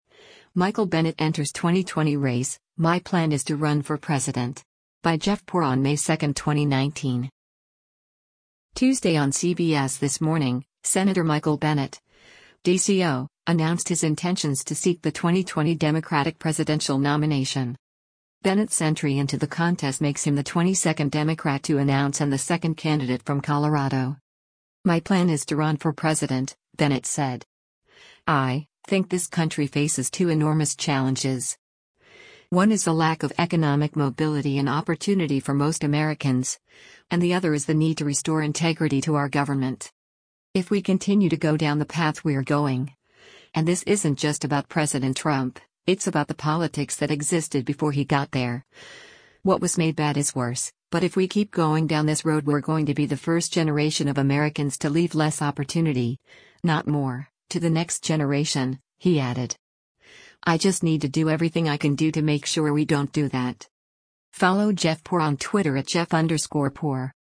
Tuesday on “CBS This Morning,” Sen. Michael Bennet (D-CO) announced his intentions to seek the 2020 Democratic presidential nomination.